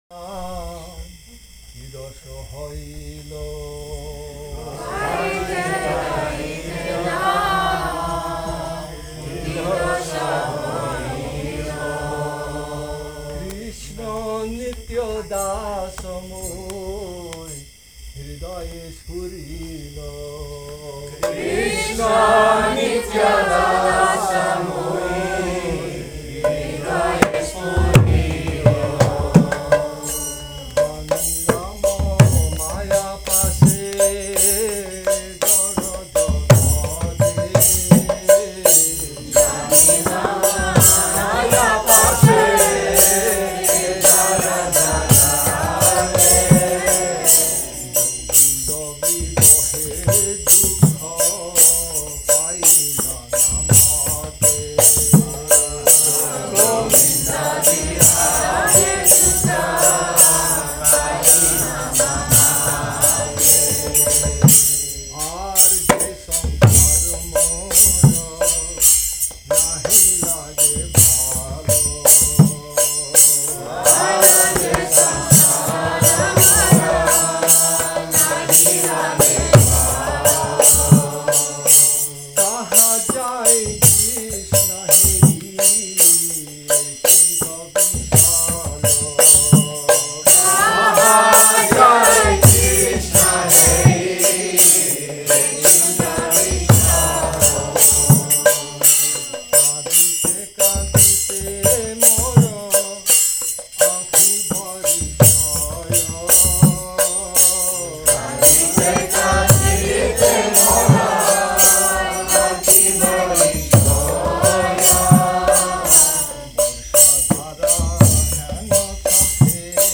Govinda Farm, Thailand | «Шри Шикшаштакам» (песня 7 а, б, c).
Ферма Говинда, Таиланд